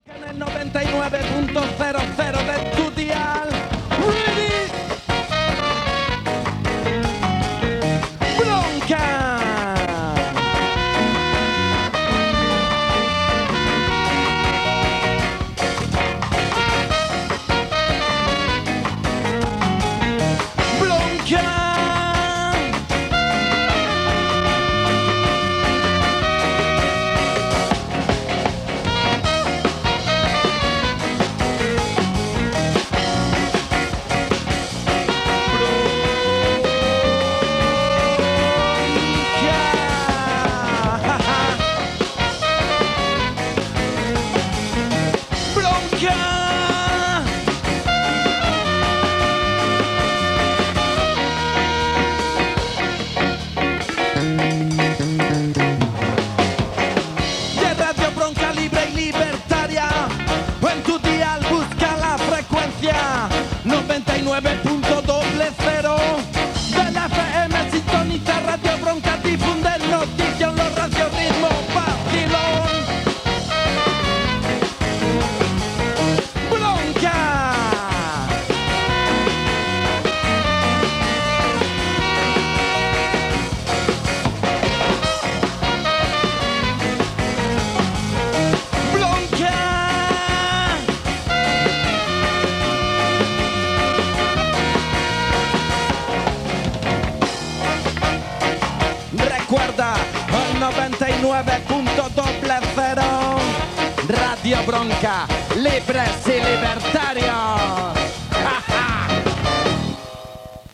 Cançó